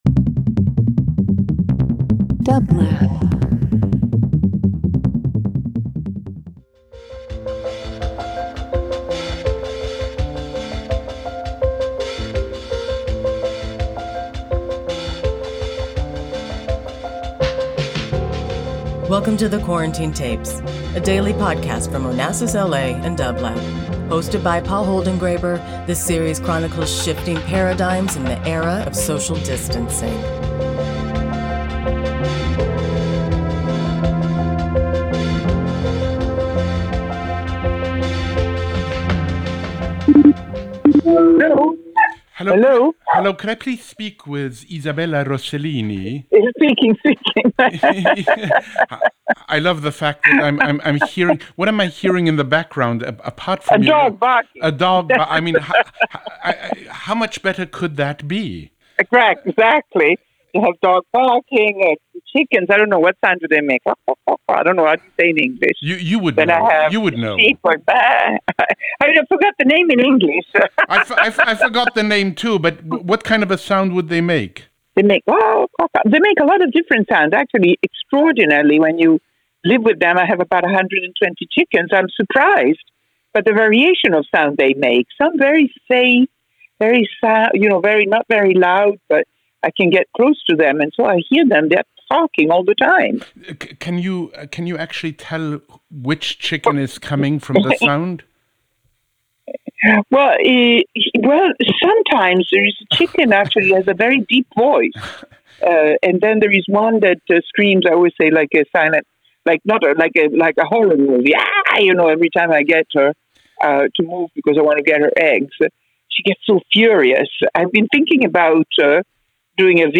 Hosted by Paul Holdengräber, the series chronicles shifting paradigms in the age of social distancing. Each day, Paul calls a guest for a brief discussion about how they are experiencing the global pandemic.